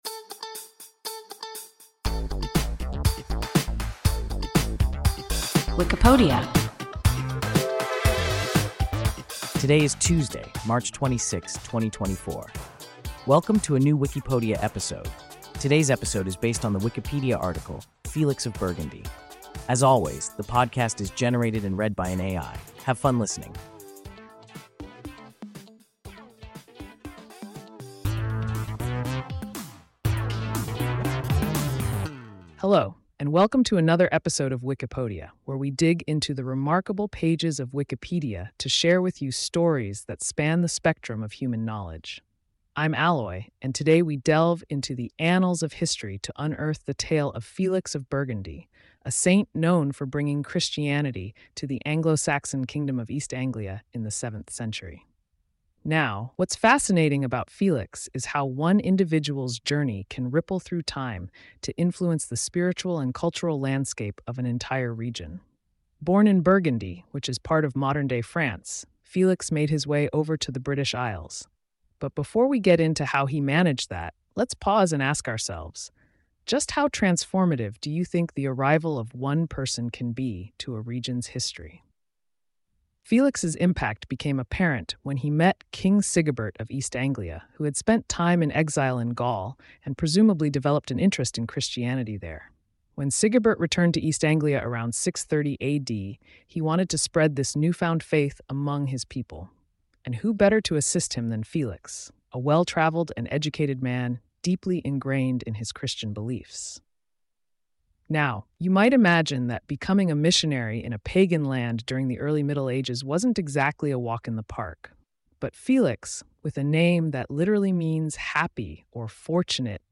Felix of Burgundy – WIKIPODIA – ein KI Podcast